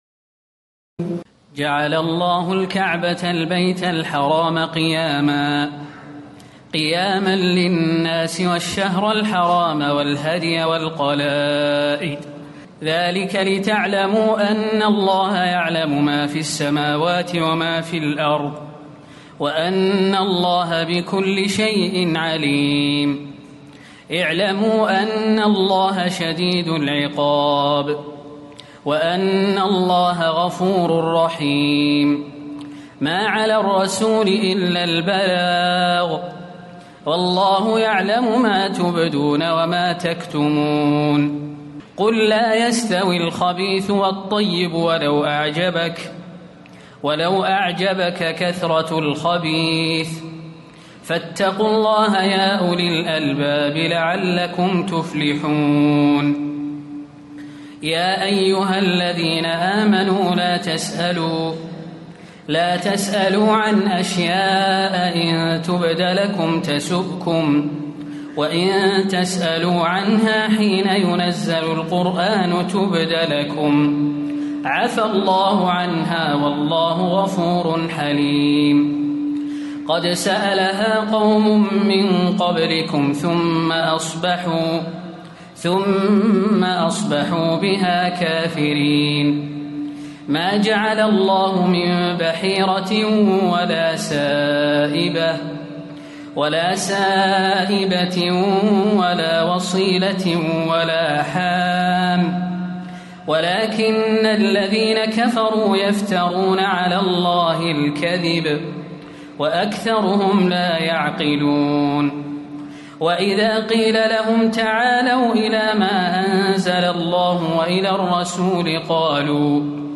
تراويح الليلة السابعة رمضان 1437هـ من سورتي المائدة (97-120) و الأنعام (1-67) Taraweeh 7 st night Ramadan 1437H from Surah AlMa'idah and Al-An’aam > تراويح الحرم النبوي عام 1437 🕌 > التراويح - تلاوات الحرمين